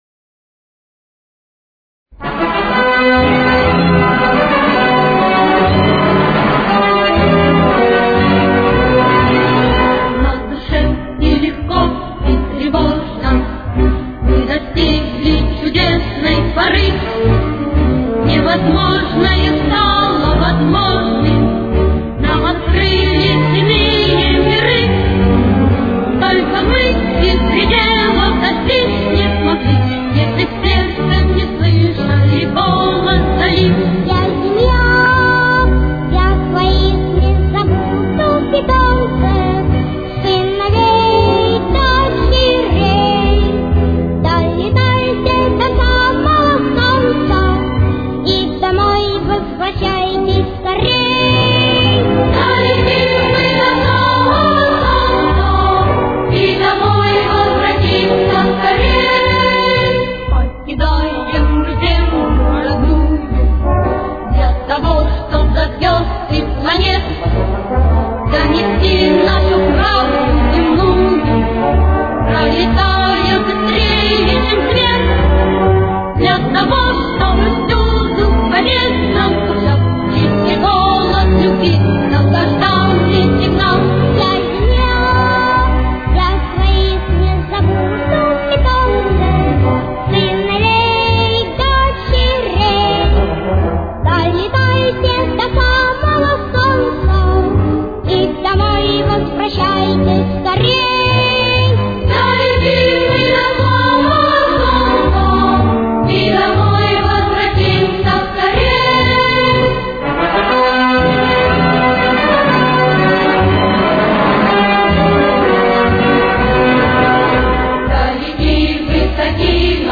Си минор. Темп: 123.